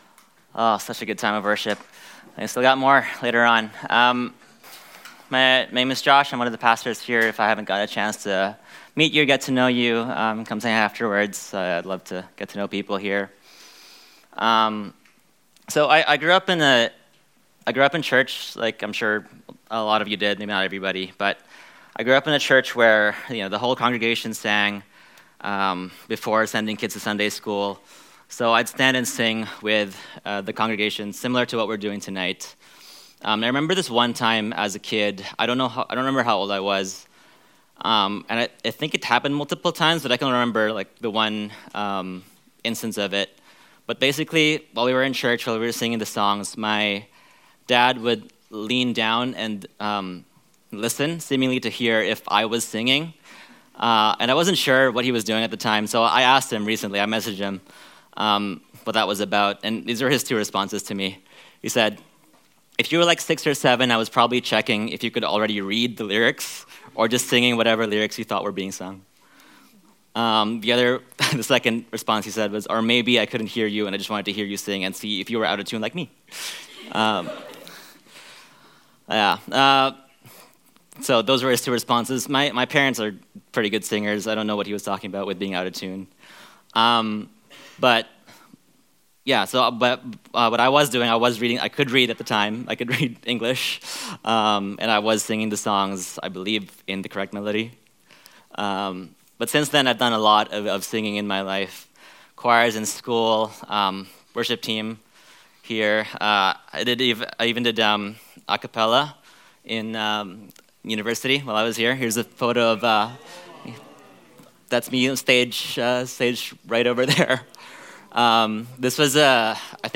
Our Worship Night was focused on praising God through the gift of song, led by our amazing musicians on our worship teams.